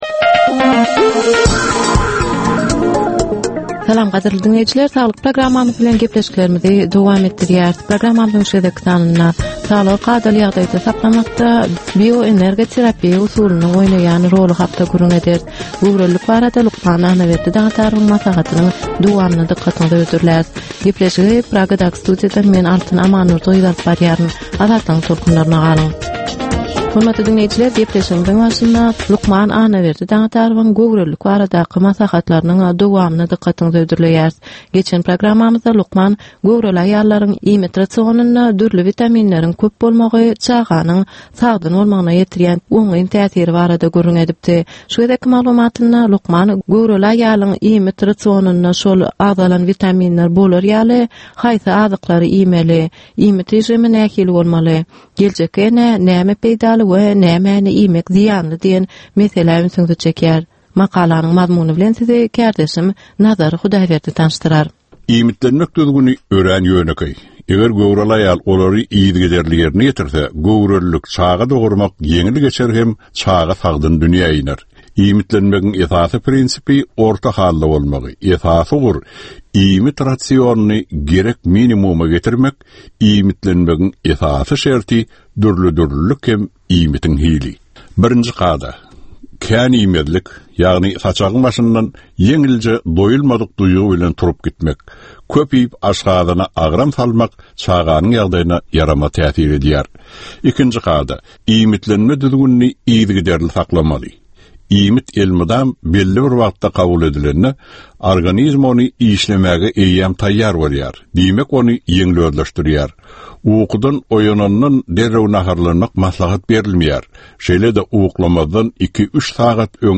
Ynsan saglygyny gorap saklamak bilen baglanysykly maglumatlar, täzelikler, wakalar, meseleler, problemalar we çözgütler barada 10 minutlyk ýörite geplesik.